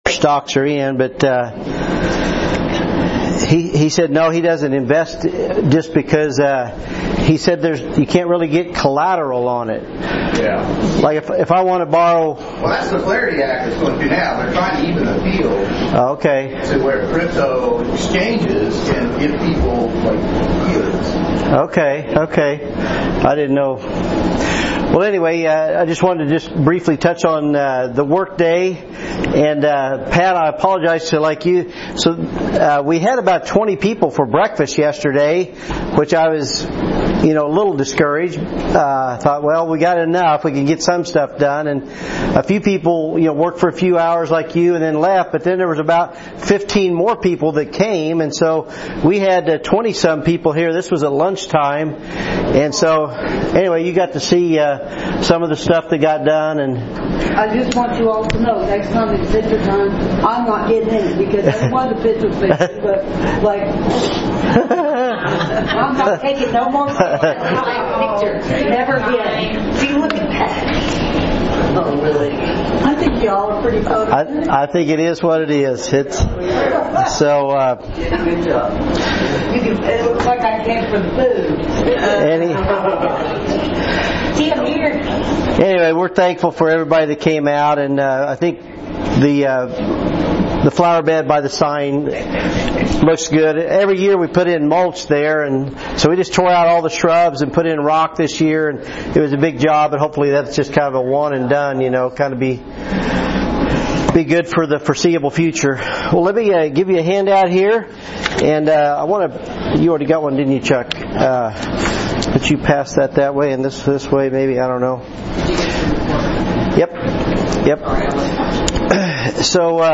The Book of Revelation Current Sermon Revelation Lesson 17b